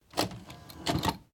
VHS_Load.wav